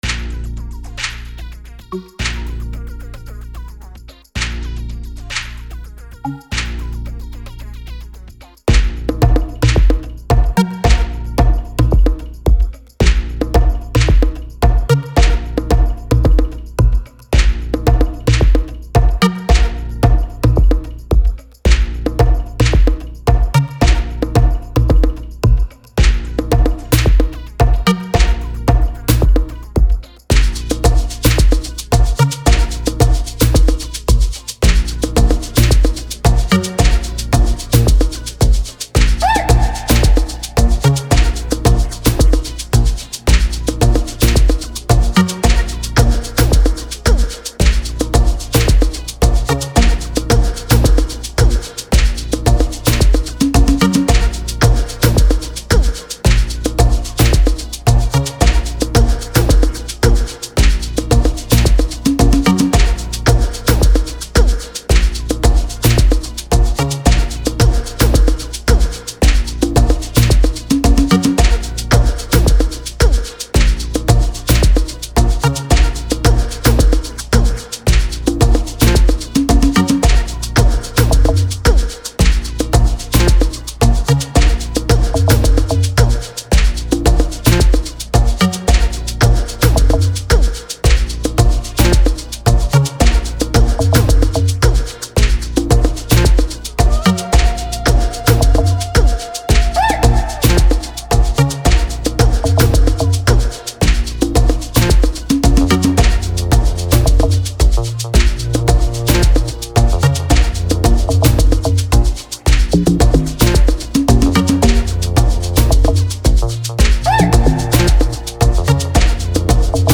04:40 Genre : Amapiano Size